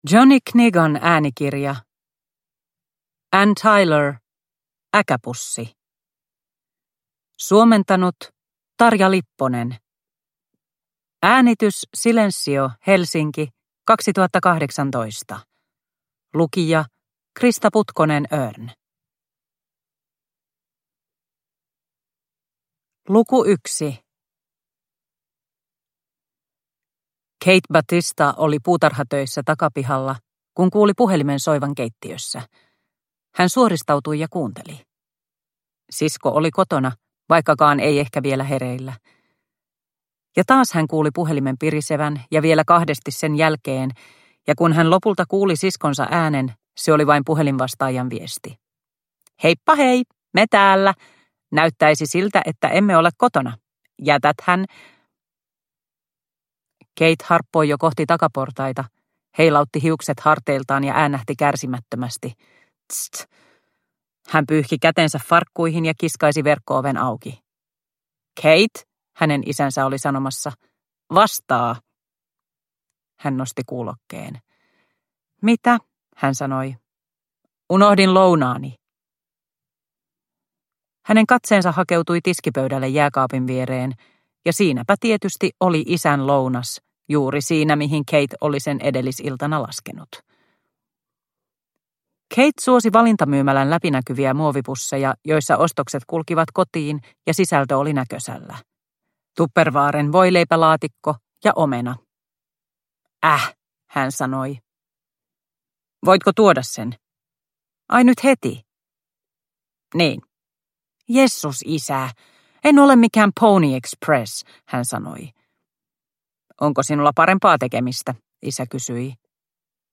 Äkäpussi – Ljudbok – Laddas ner